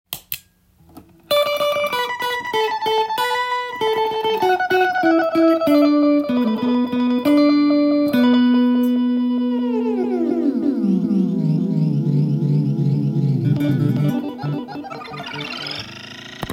飛び道具的な変わった音がするエフェクターを作っていることで有名です。
試しに弾いてみました
ディレイの部類になるので
なんだかよくわからないノイジーなサウンドを思い求めている人に